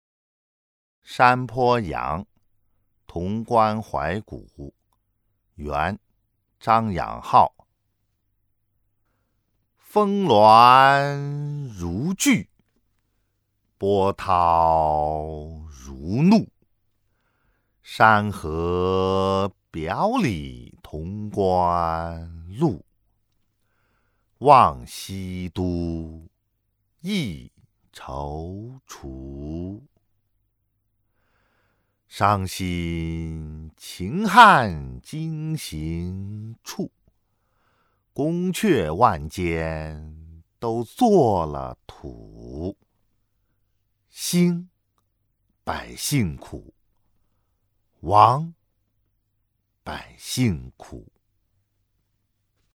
［元］张养浩《山坡羊·潼关怀古》（读诵）